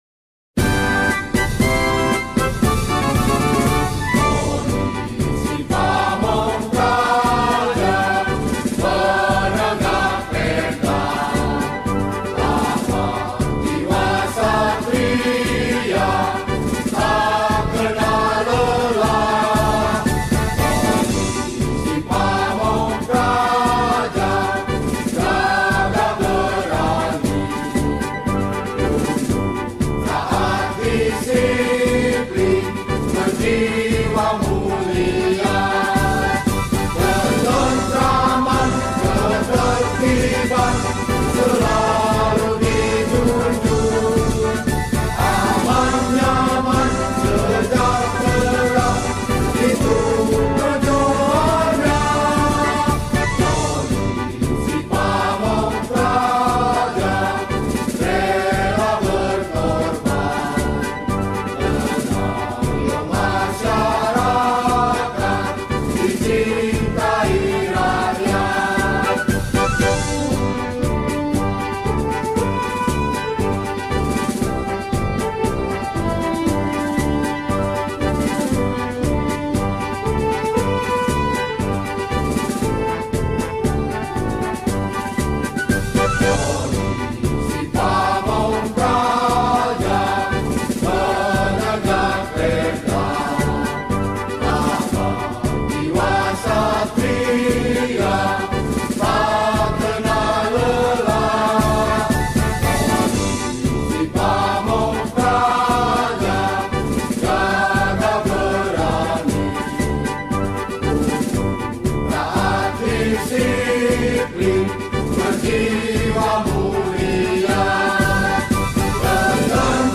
Mars Satpol PP